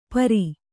♪ pari